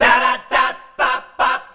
DARABAB C.wav